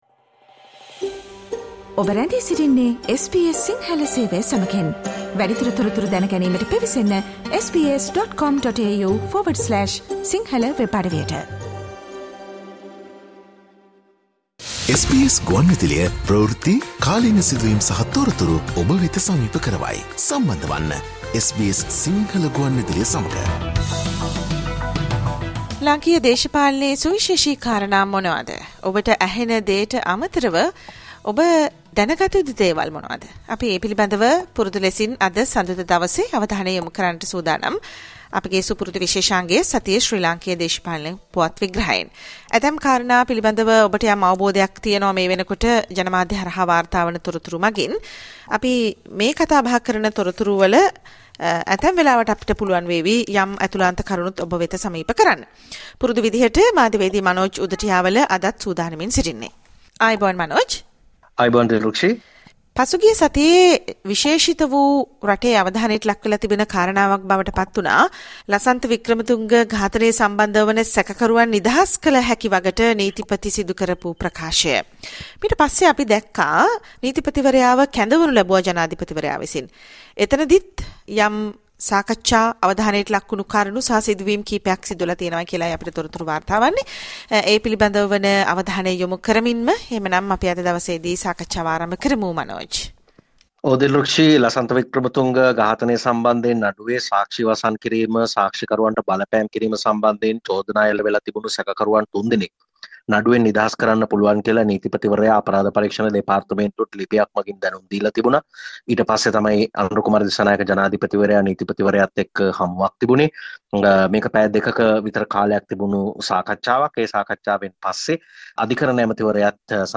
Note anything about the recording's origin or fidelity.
Please note that the two segments broadcasted during the live program have been combined and are now available on the website as a single program.